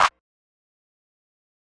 [CLAP] Dro 1.wav